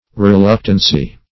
\Re*luc"tan*cy\ (-tan-s?), n. [See Reluctant.]